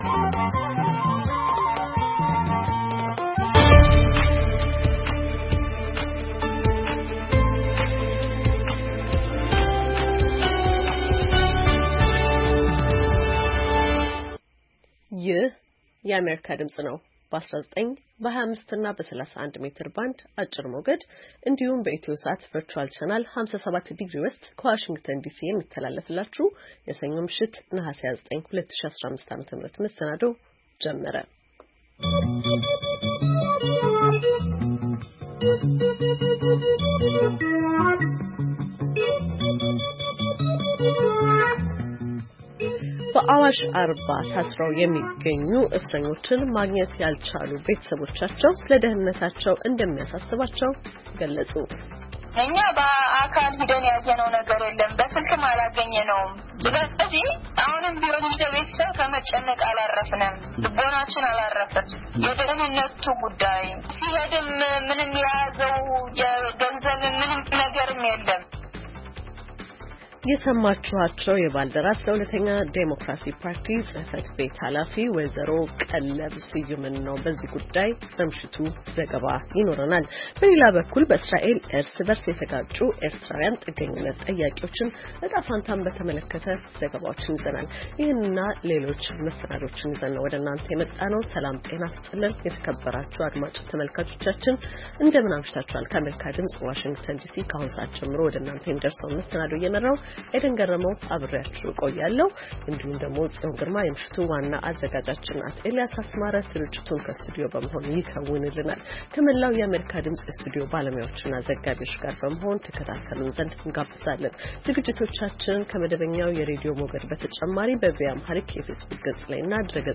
ሰኞ፡-ከምሽቱ ሦስት ሰዓት የአማርኛ ዜና